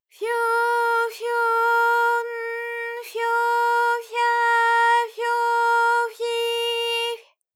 ALYS-DB-001-JPN - First Japanese UTAU vocal library of ALYS.
fyo_fyo_n_fyo_fya_fyo_fyi_fy.wav